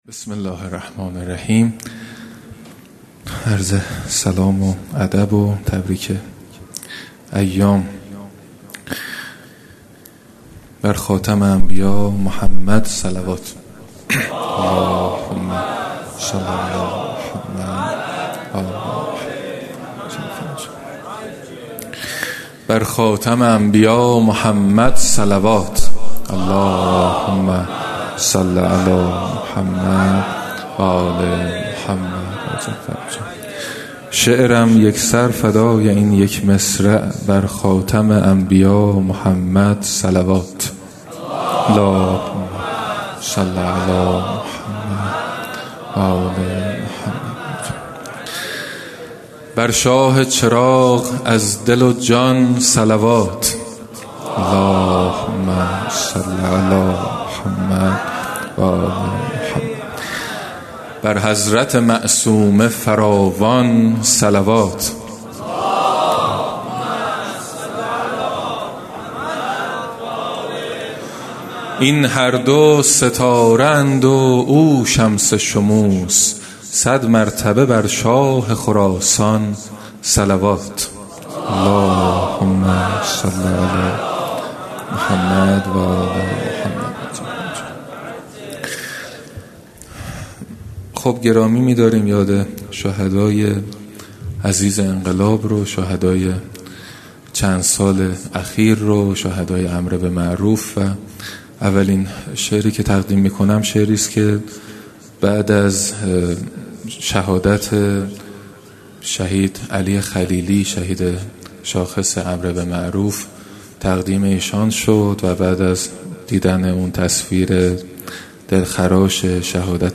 جلسه هفتگی
یادواره شهدای امر به معروف و نهی از منکر و گرامیداشت هفته عفاف و حجاب